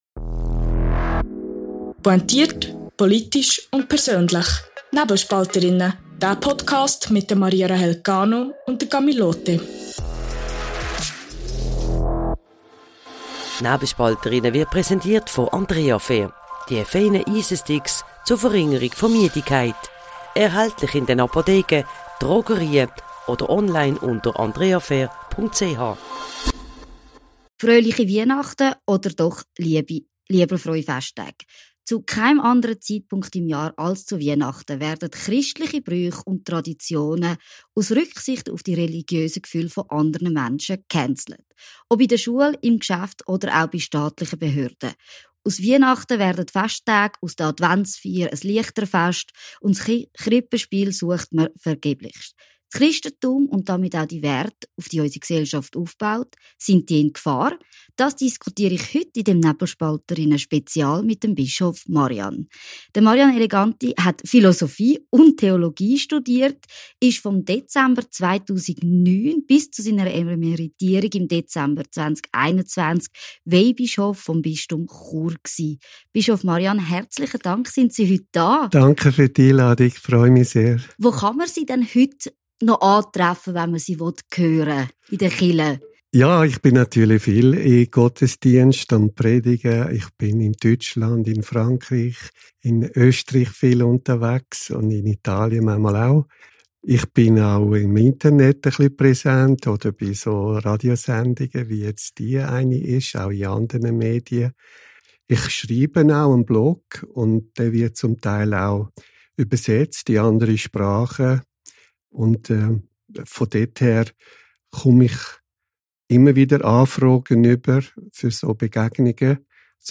Ein Gespräch über den Niedergang christlicher Werte, die Gefahr des Islamismus und die wahre Bedeutung von Weihnachten.